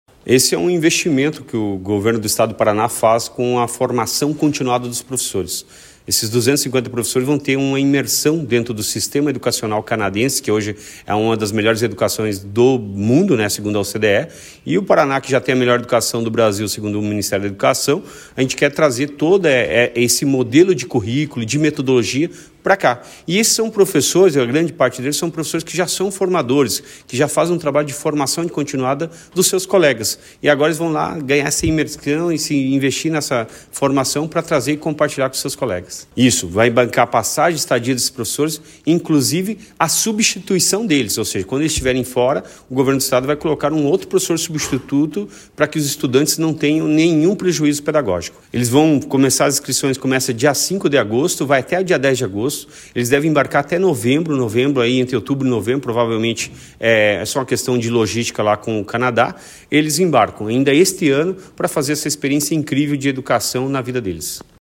Sonora do secretário da Educação, Roni Miranda, sobre a segunda edição do programa Ganhado o Mundo Professor